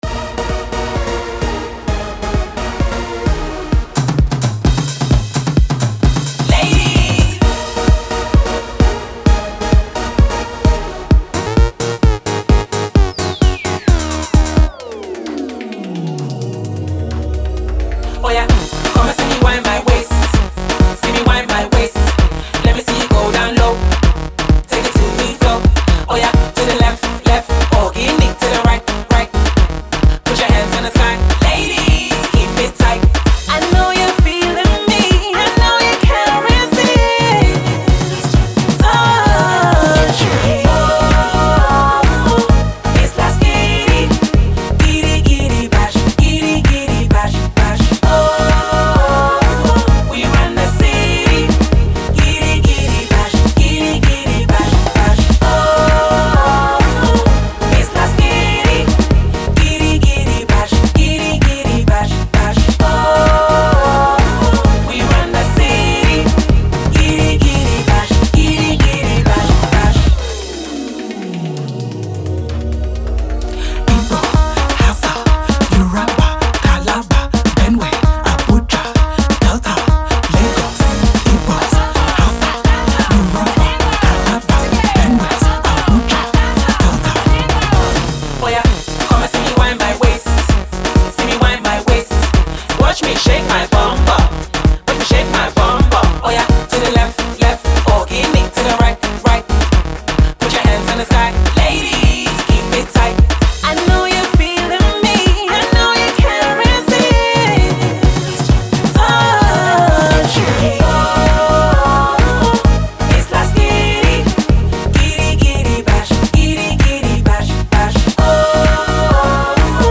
UK-based Nigerian girl group
mash-up genre Dance tune